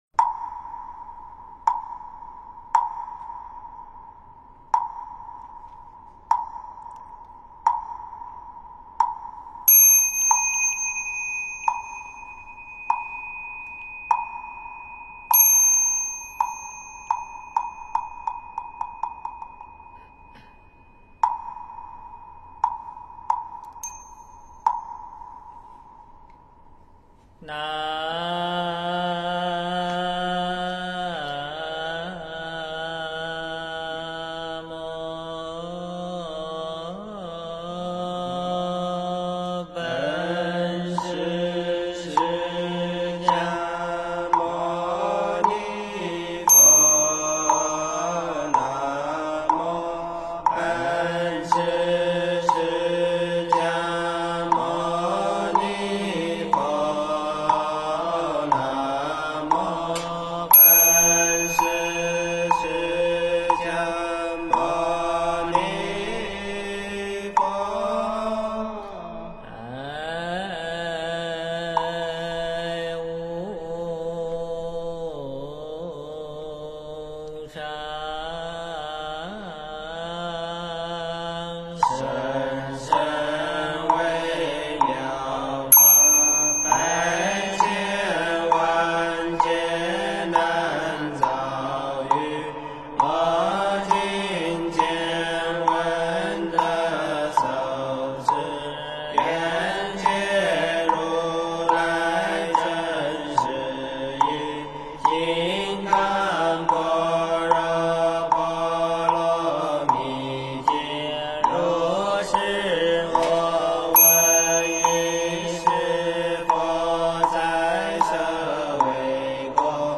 曲目2 诵经 曲目2--佛教音乐 点我： 标签: 佛音 诵经 佛教音乐 返回列表 上一篇： 弥勒佛 下一篇： 消災吉祥神咒 相关文章 Vajra Sattva--琼英卓玛 Vajra Sattva--琼英卓玛...